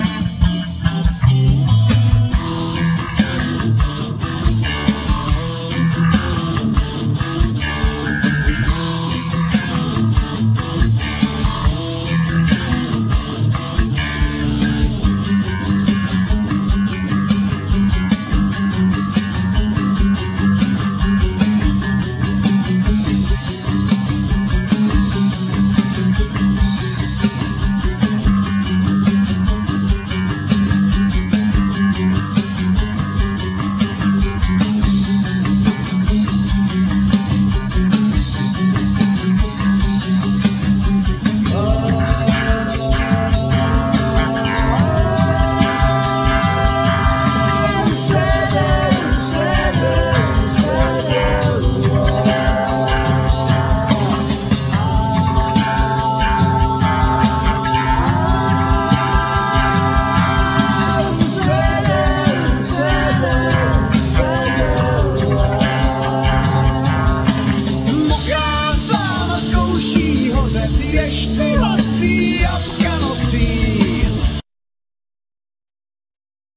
Voice
Bass
Guitar
Cimbalum,Vocal
Saxophone,Bassoon
Violin